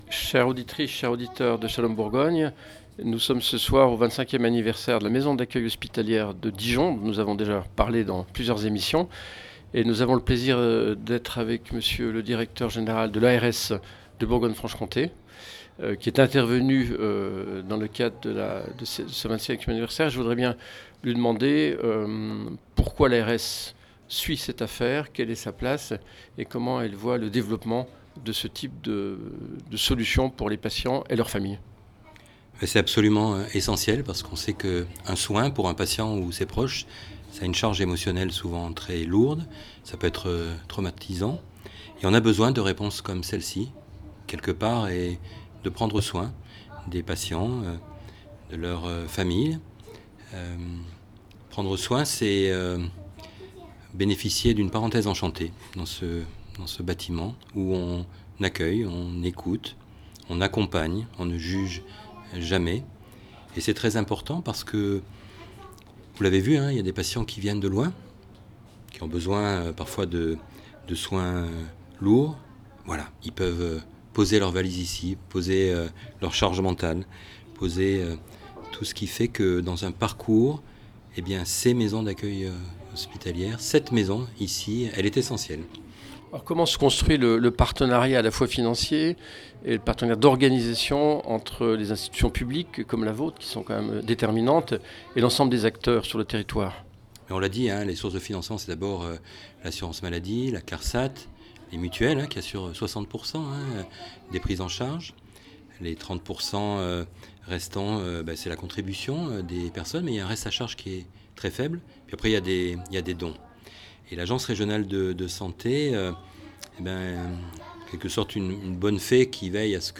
Shalom Bourgogne était présente au 25ieme anniversaire de la Maison d'accueil
Nous avons pu interrogé Monsieur Coiplet, Directeur général de l’ARS BFC.